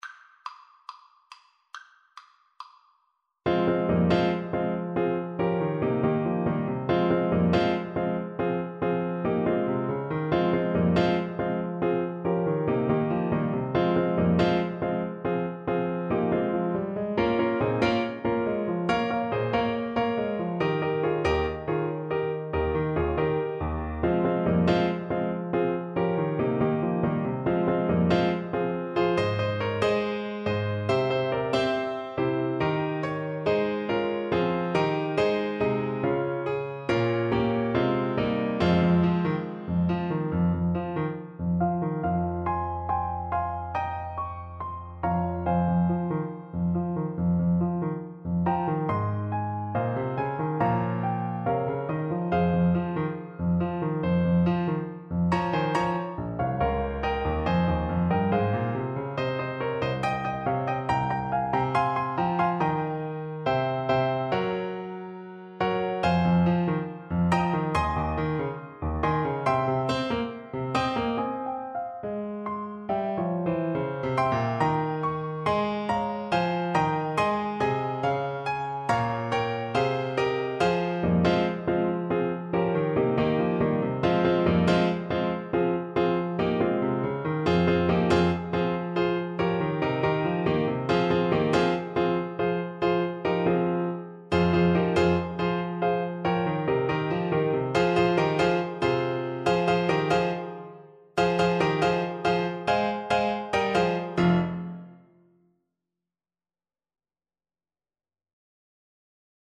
with a rock twist
4/4 (View more 4/4 Music)
Driving forward = c. 140
Christmas (View more Christmas French Horn Music)